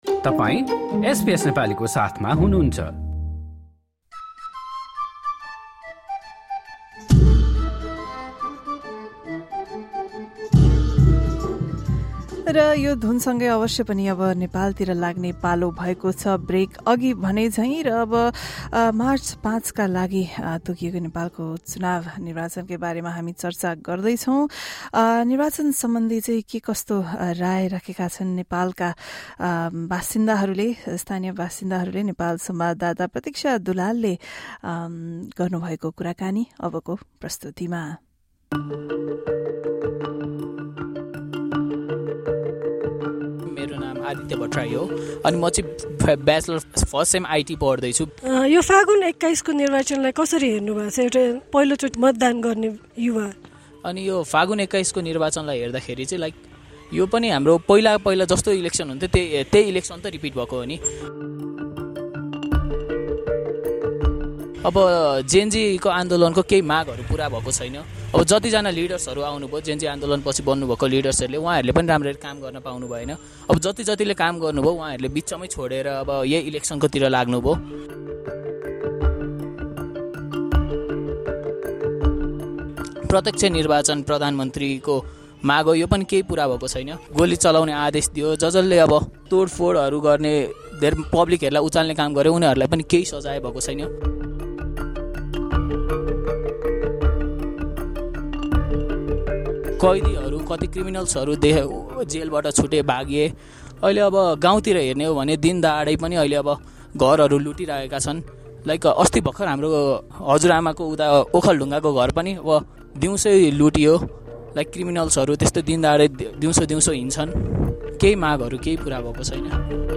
spoke with first-time voters